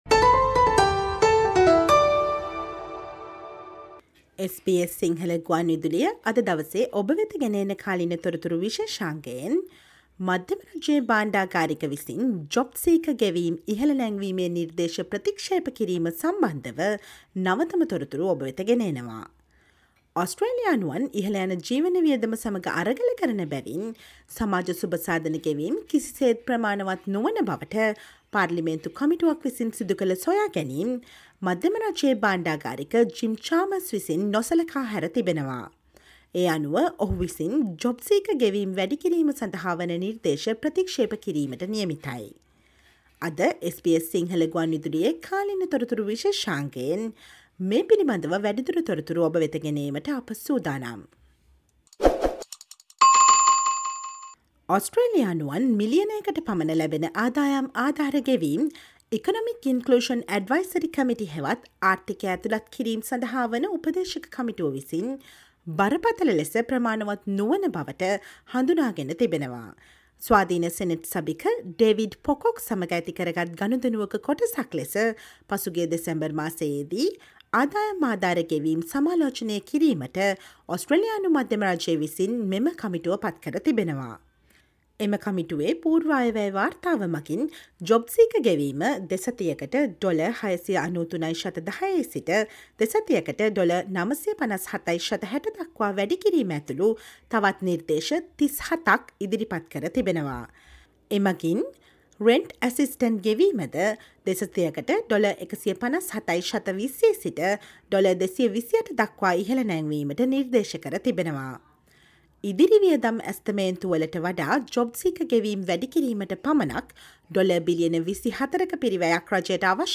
Listen to the SBS Sinhala radio current affair feature on the latest updates of Treasurer, Jim Chalmers' rejection of a recommendation to increase JobSeeker payments despite a parliamentary committee's findings and the responses of opposition and other parties for that.